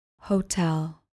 Pronounced: hoh-TELL